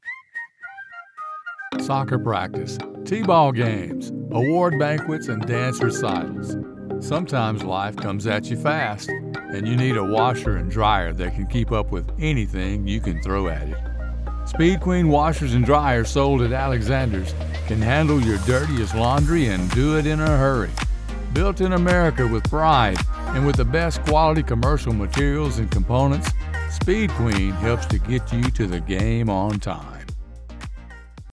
audio only speed queen ad
General American, Western, Mid Western and Southern
Young Adult
Middle Aged
Senior
Commercial
Speed Queen2A_Whistle.mp3